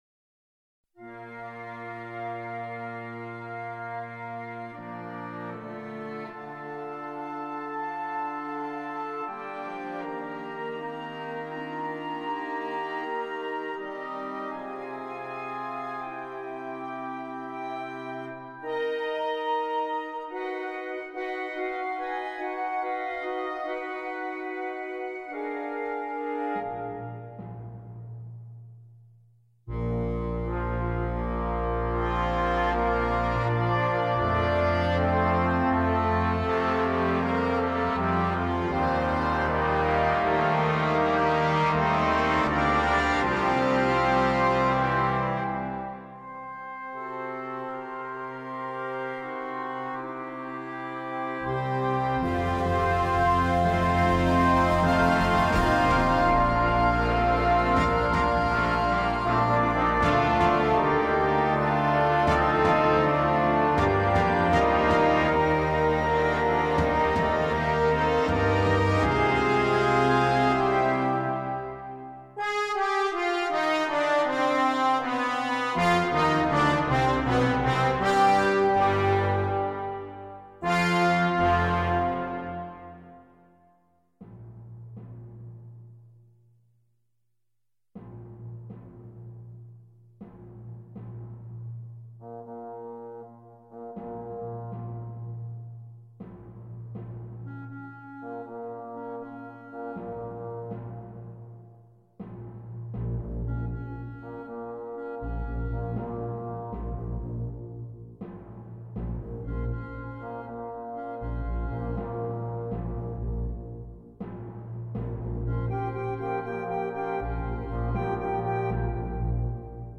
zweisätziges Werk für Blasorchester